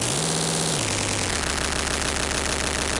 科幻元素 " 干扰01
描述：信号干扰。外星人的沟通。
标签： 外星科技 毛刺 科幻 干扰 信号 通信 科幻
声道立体声